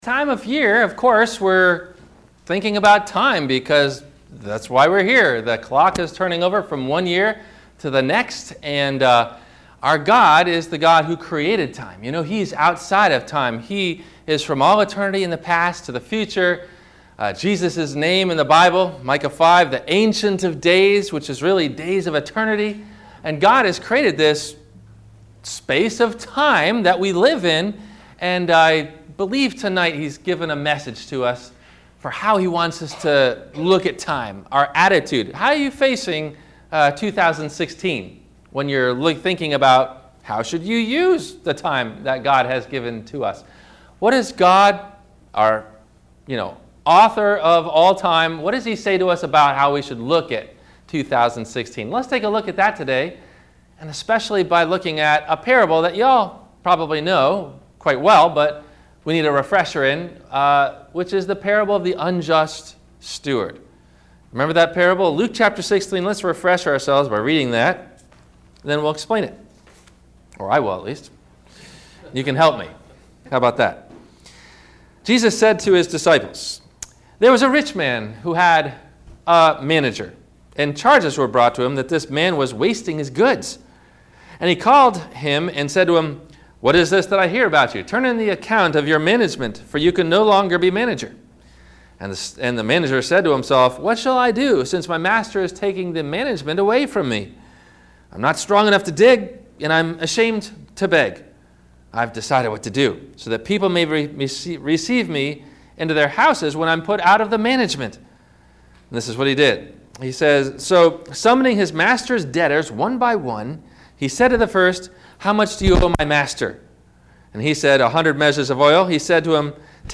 Questions to think about before you hear the Sermon: